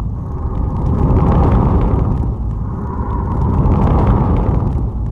3098b9f051 Divergent / mods / Soundscape Overhaul / gamedata / sounds / monsters / poltergeist / fire_idle_0.ogg 42 KiB (Stored with Git LFS) Raw History
fire_idle_0.ogg